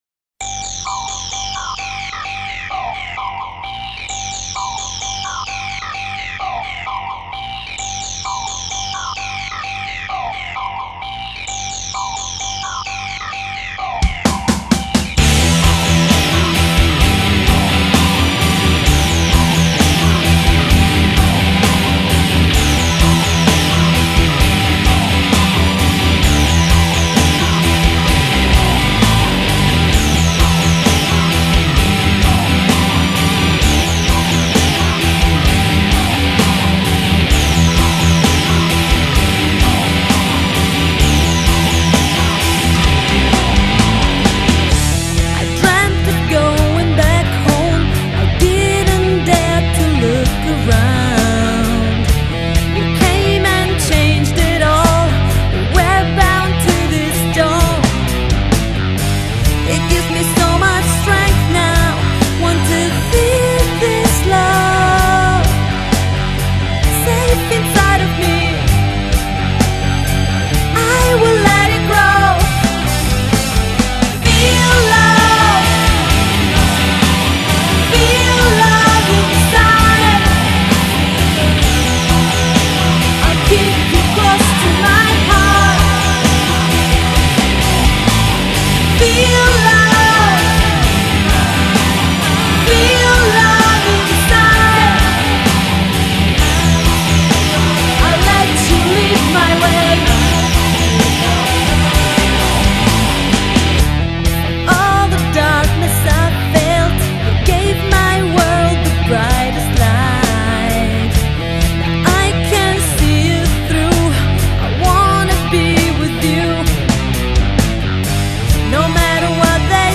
Vocals
Guitars, Bass, Keyboard, Programming
Drums